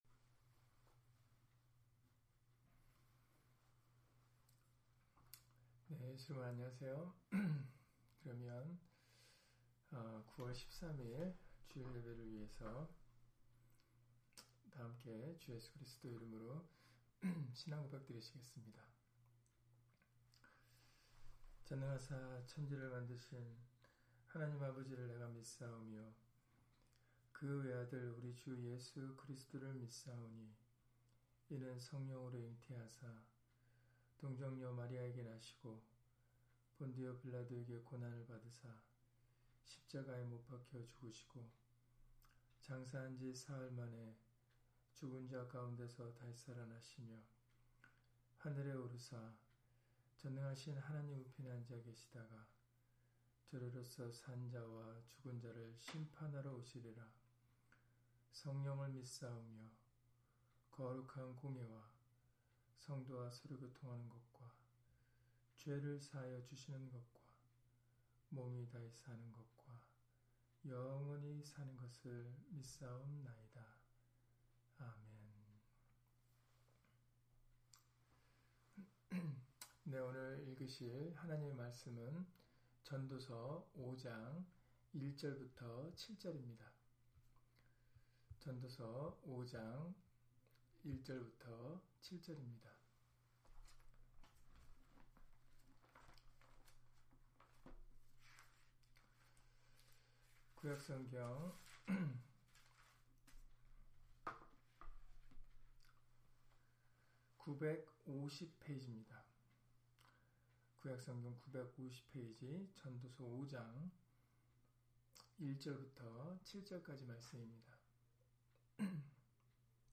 전도서 5장 1-7절 [경솔히 말하지 말자] - 주일/수요예배 설교 - 주 예수 그리스도 이름 예배당